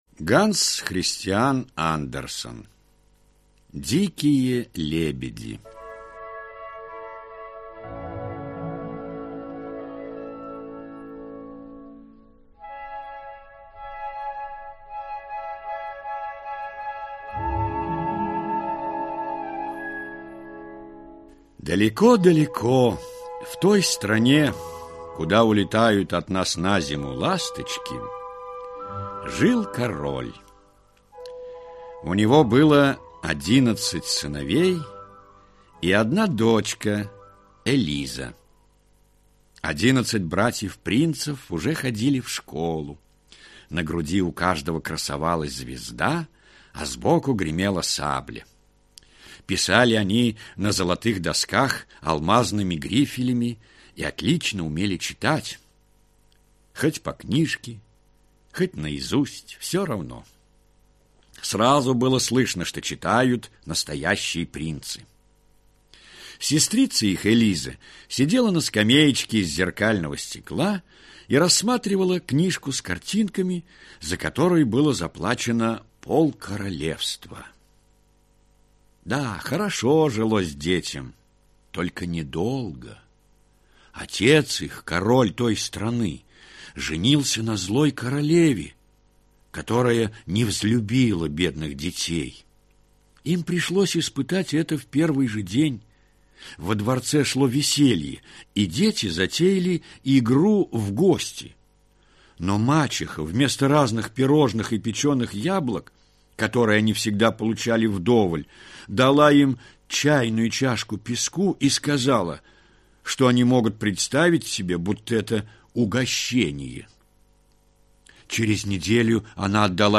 Аудиокнига Дикие лебеди | Библиотека аудиокниг